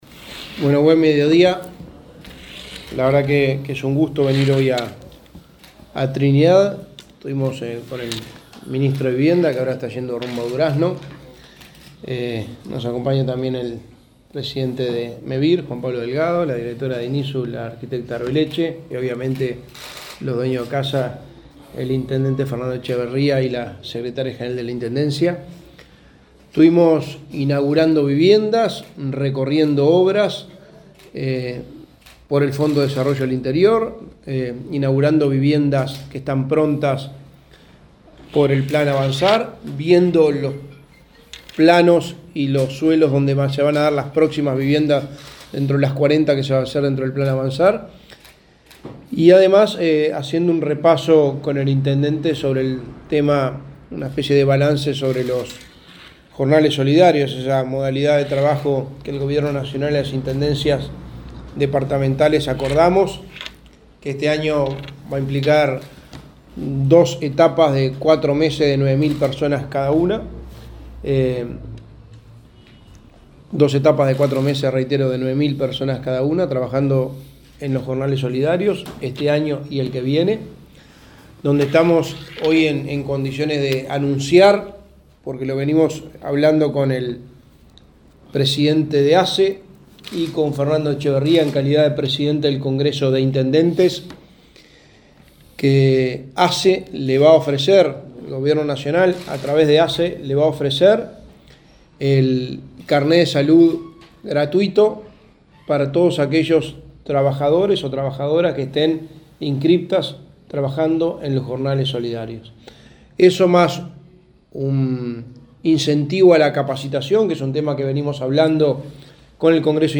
Conferencia de prensa en Intendencia de Flores
Conferencia de prensa en Intendencia de Flores 27/07/2023 Compartir Facebook X Copiar enlace WhatsApp LinkedIn El secretario de Presidencia, Alvaro Delgado; el Intendente de Flores, Fernando Echeverría, y el presidente de Mevir, Juan Pablo Delgado, brindaron una conferencia de prensa este jueves 27, en el marco de una gira por ese departamento.